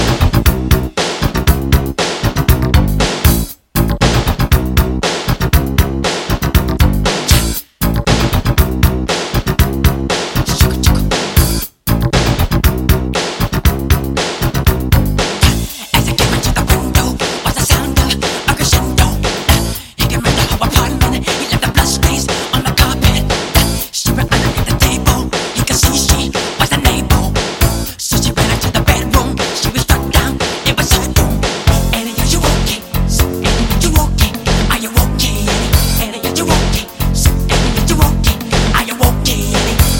dance pop